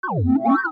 Déplacement 2.mp3